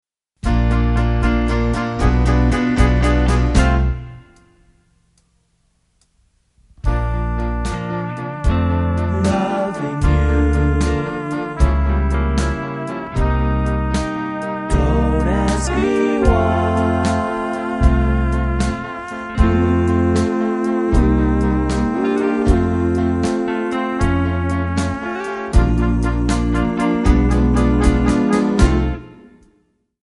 Bb
MPEG 1 Layer 3 (Stereo)
Backing track Karaoke
Pop, Oldies, 1950s